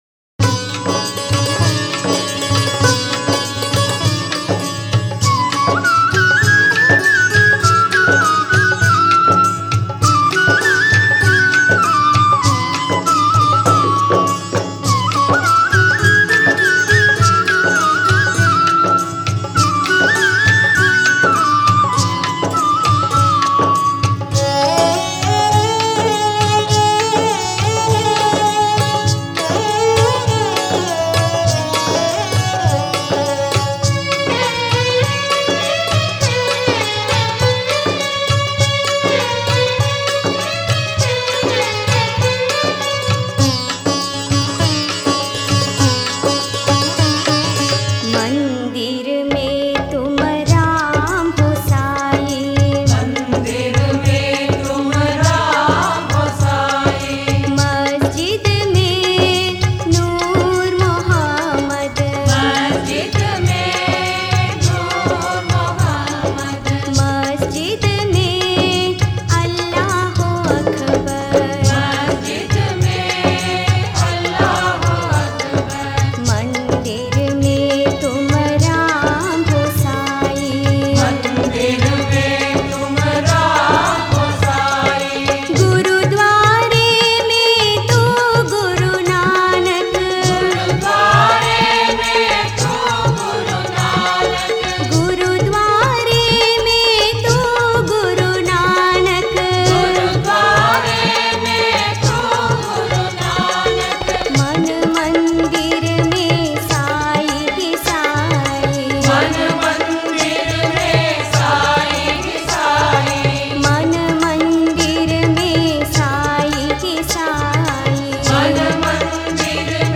Author adminPosted on Categories Sarva Dharma Bhajans